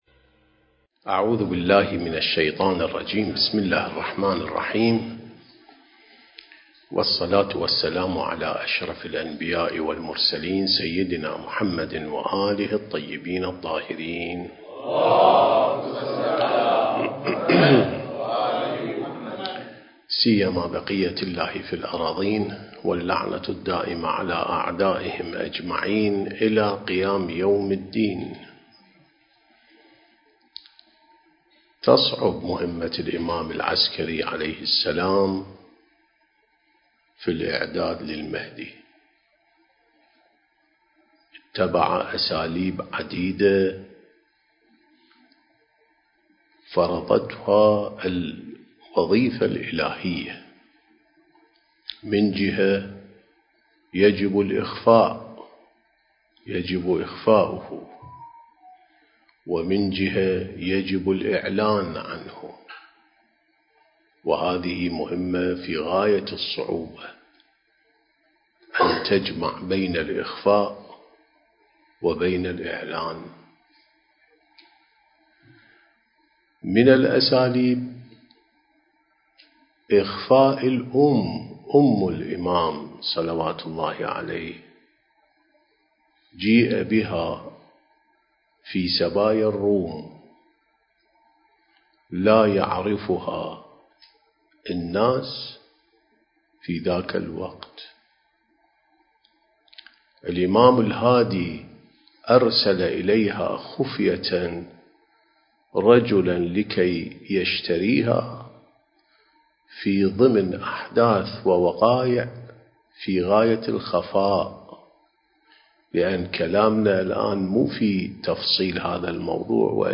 سلسلة محاضرات: الإعداد الربّاني للغيبة والظهور (12)